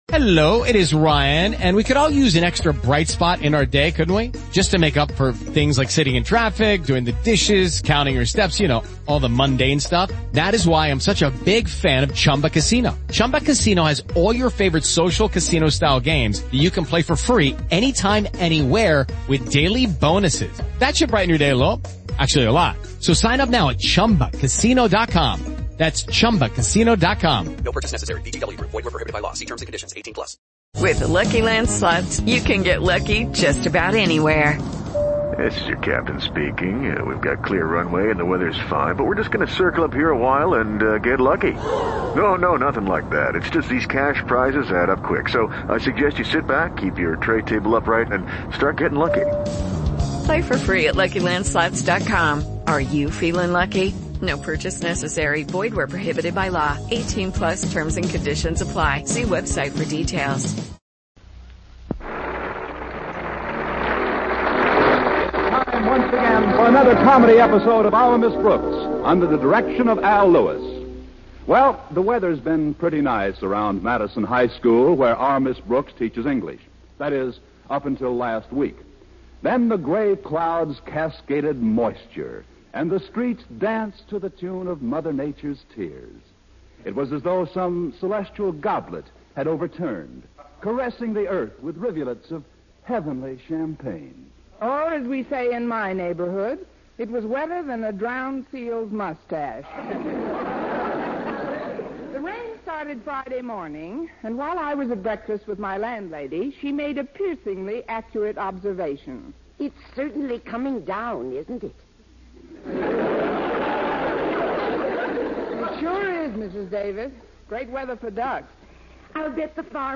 Our Miss Brooks was a beloved American sitcom that ran on CBS radio from 1948 to 1957.
The show starred the iconic Eve Arden as Connie Brooks, a wisecracking and sarcastic English teacher at Madison High School. Arden's portrayal of Miss Brooks was both hilarious and endearing, and she won over audiences with her quick wit and sharp one-liners The supporting cast of Our Miss Brooks was equally memorable. Gale Gordon played the uptight and pompous Principal Osgood Conklin, Richard Crenna played the dimwitted but lovable student Walter Denton, and Jane Morgan played Miss Brooks' scatterbrained landlady, Mrs. Davis.